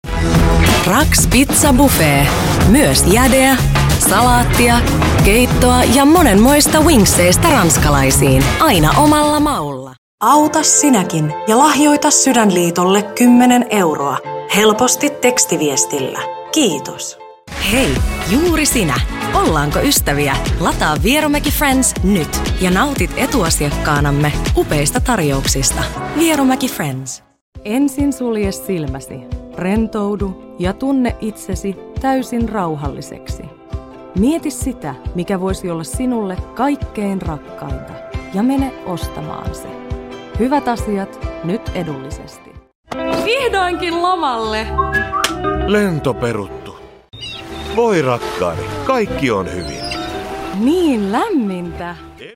Mainosdemo-Commercial-Demo-FIN.mp3